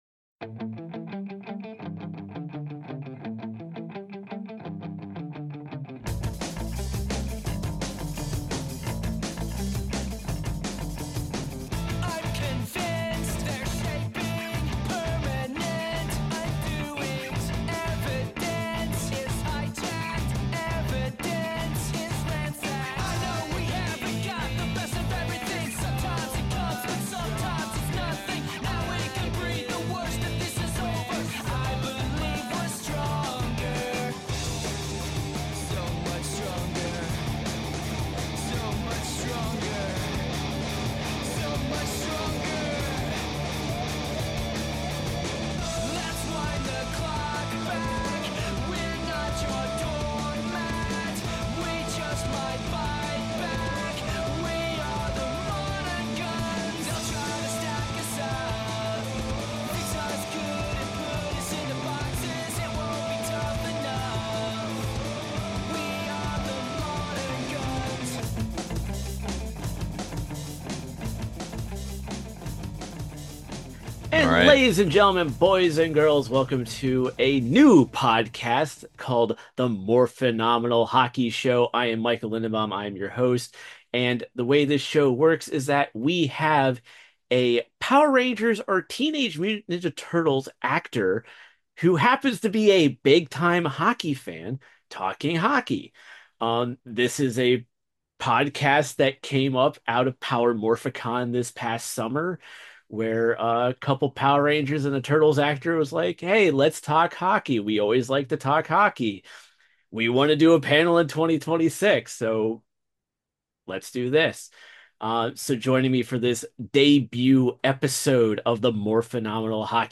Opening Music (Audio Version Only)